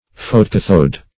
\pho`to*cath"ode\ (f[=o]`t[-o]*k[a^]th"[=o]d)